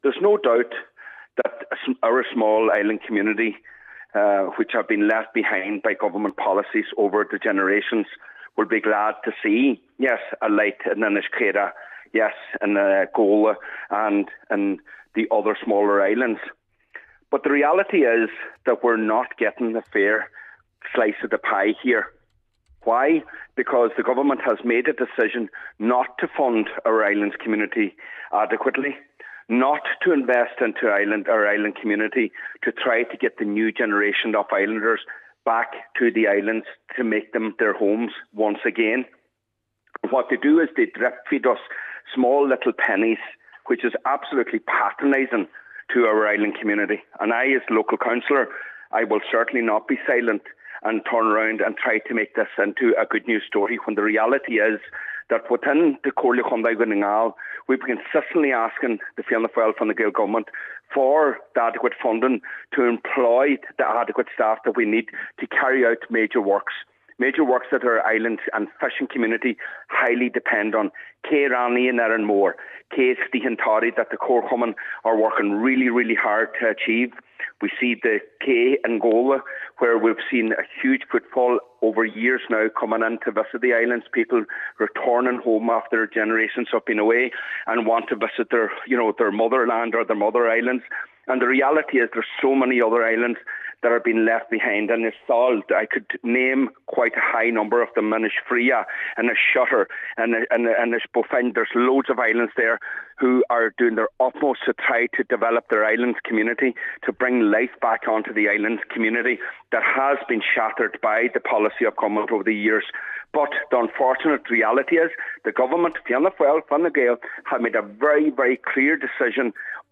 Meanwhile, Cllr Michael Choilm Mac Giolla Easbuig maintains that funding is being drip-fed and not enough is being allocated: